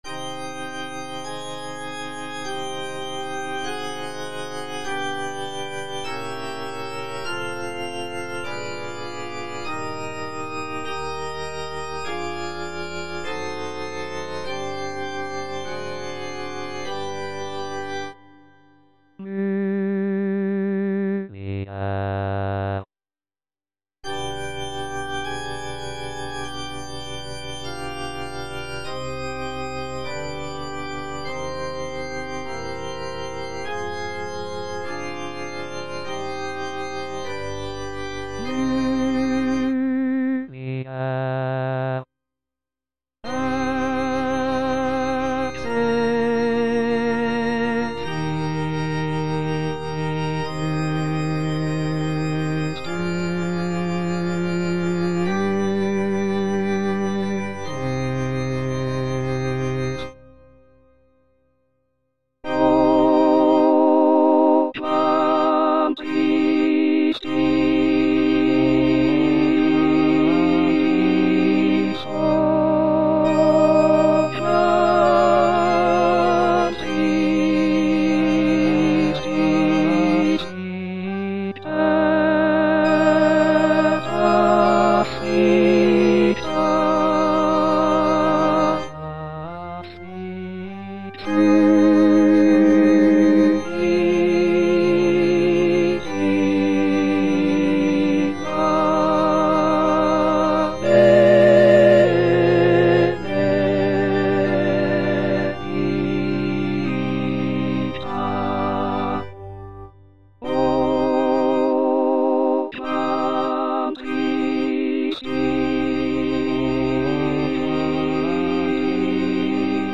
Parole 3: Mulier, ecce filius tuus        Prononciation gallicane (à la française)
Tutti       La moitié est "solistes"